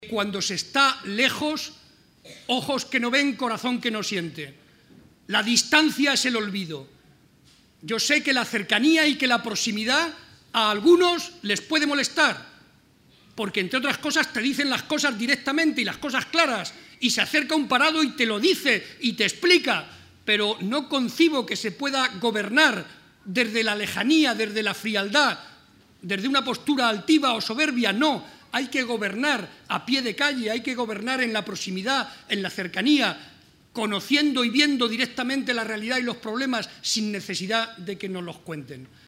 Barreda junto a Rivas en el acto celebrado en Talavera.
Barreda hizo estas manifestaciones en Talavera de la Reina, durante la presentación de la candidatura a la Alcaldía que encabeza José Francisco Rivas y que ha tenido lugar en el Instituto “Juan Antonio Castro”, donde el actual alcalde se ha formado.